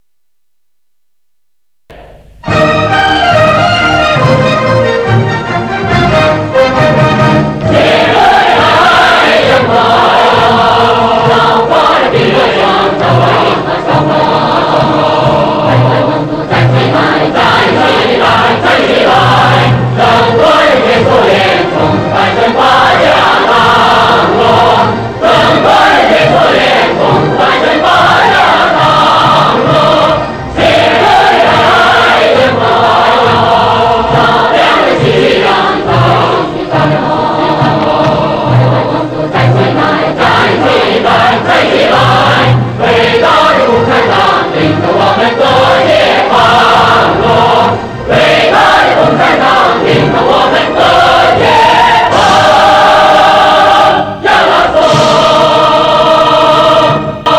（合唱）西藏民歌